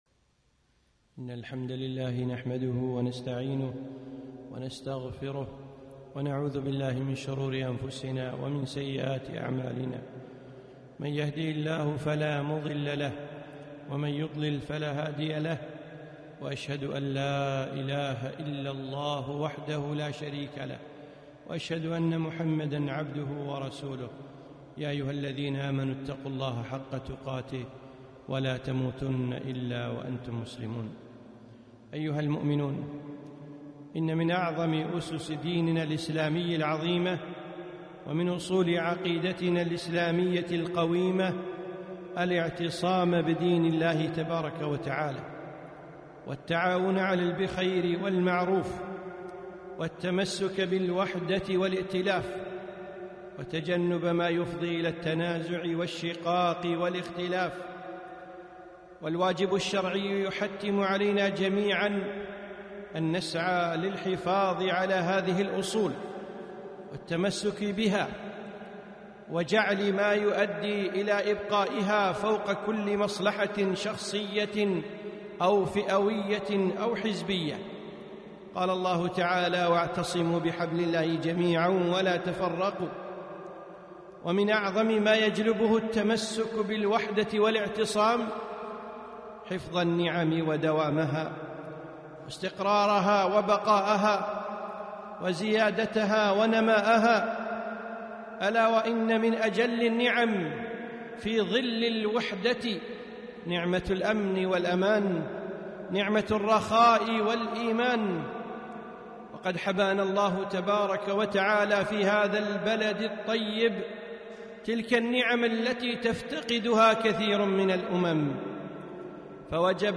خطبة - الوطن أمانة في أعناقنا